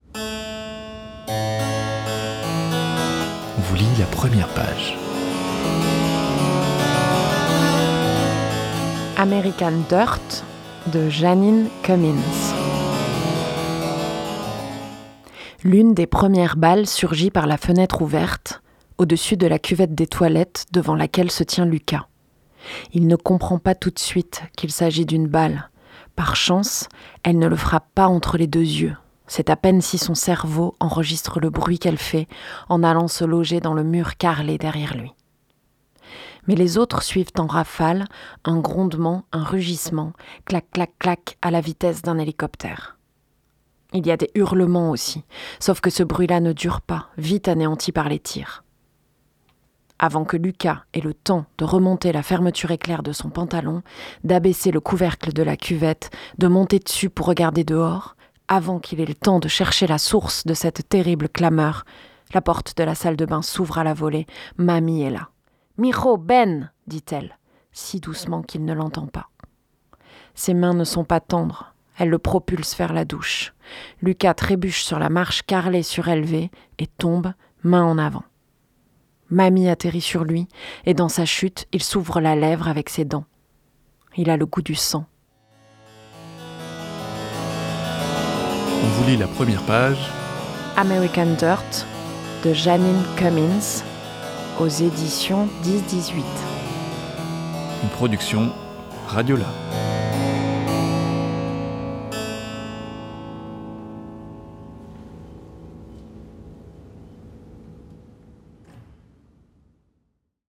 Dans l’émission Première page, RadioLà vous propose la lecture de l’incipit d’un roman.